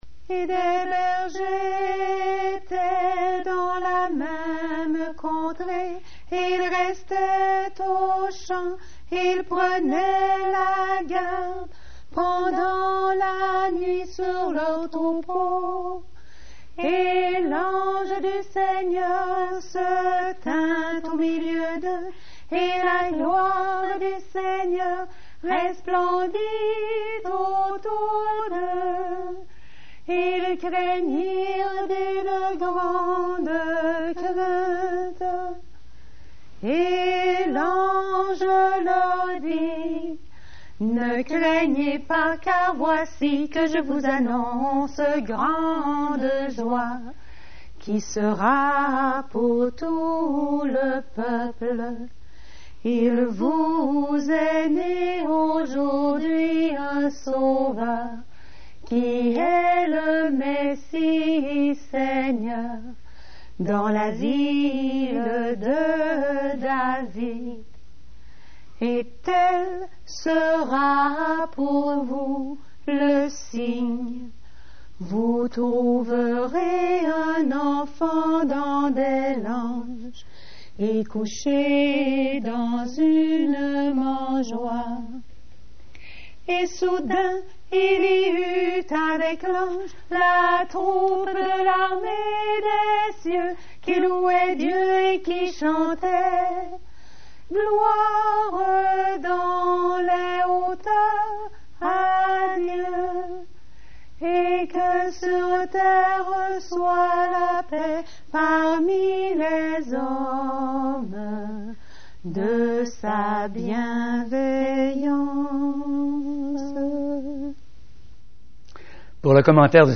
Récitatif biblique (fichier mp3)